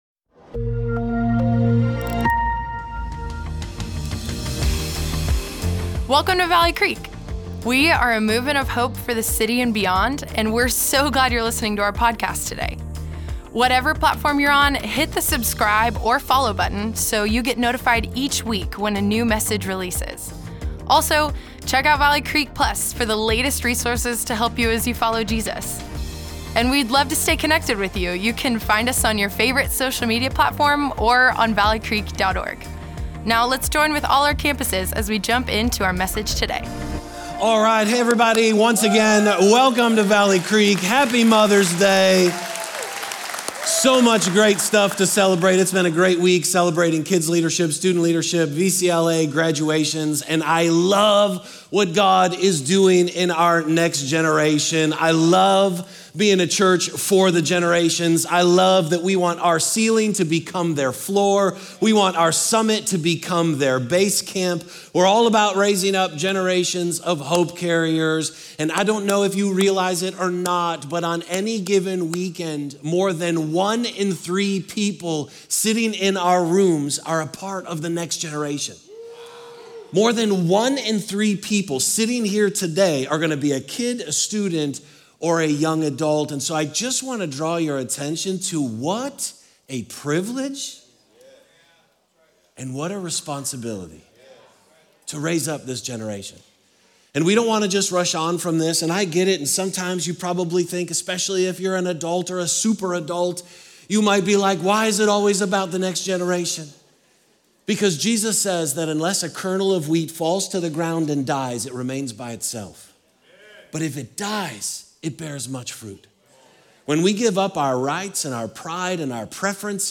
Weekend Messages